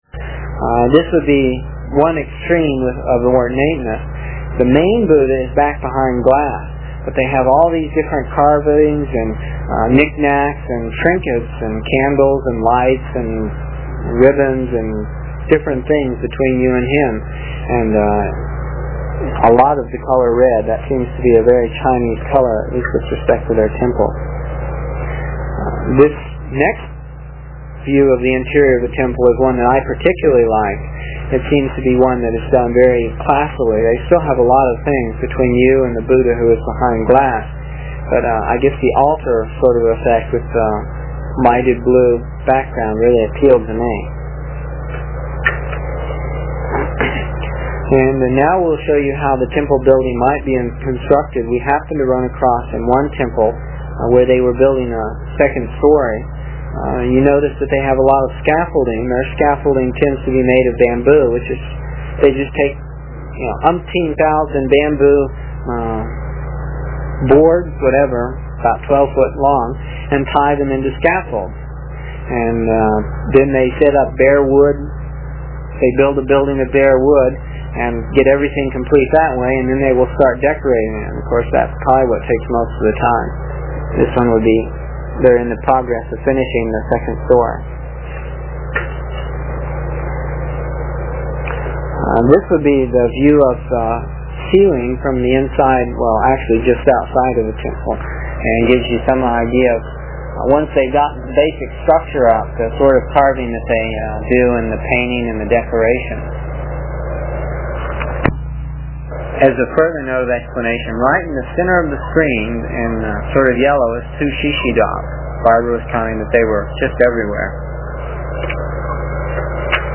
It is from the cassette tapes we made almost thirty years ago. I was pretty long winded (no rehearsals or editting and tapes were cheap) and the section for this page is about four minutes and will take about two minutes to download with a dial up connection.